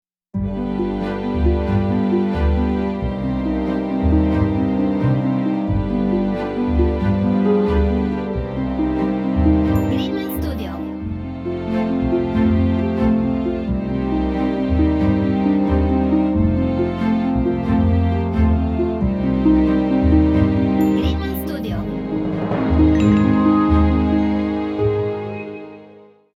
Orchestral/Cinematic